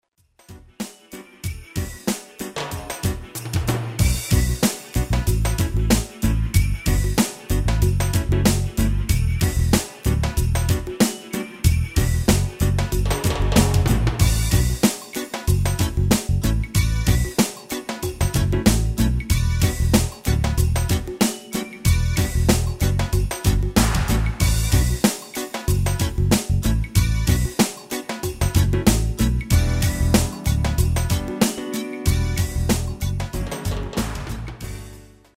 Demo/Koop midifile
Genre: Reggae / Latin / Salsa
- Géén vocal harmony tracks